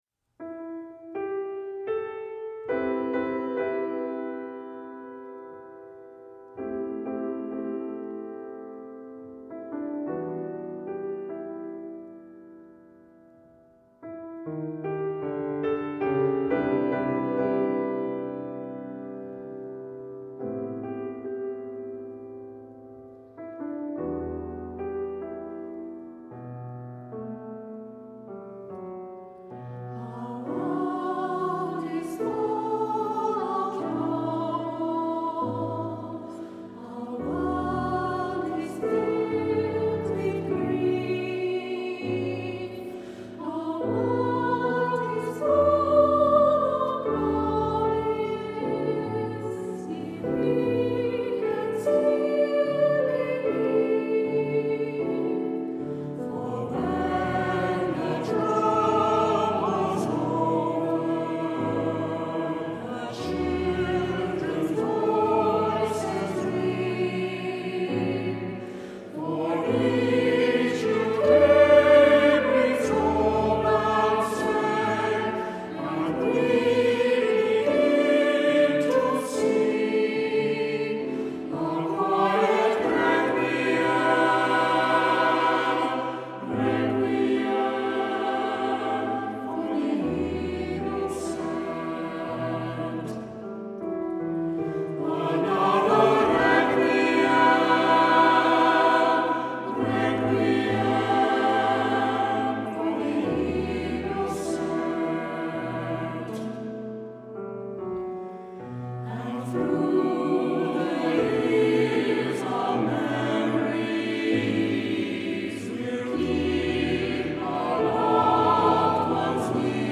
Tonart(en): e-moll